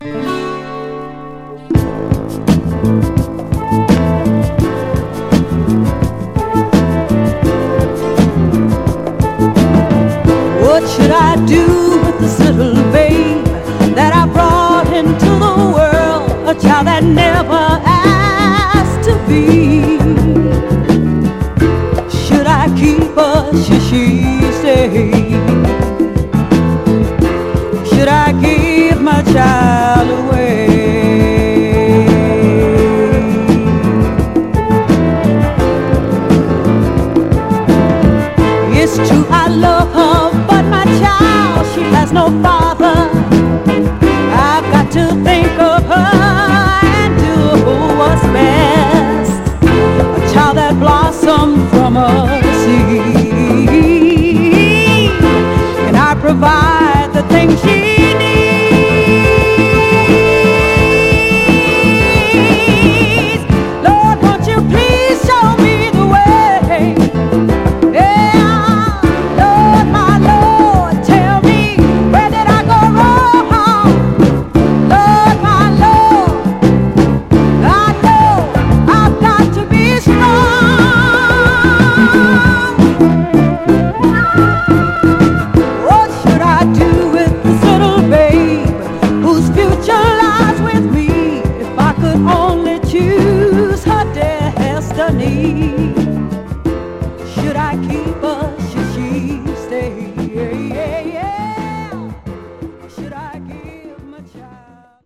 サンプリング向けグルーヴとしてもオススメです。
※試聴音源は実際にお送りする商品から録音したものです※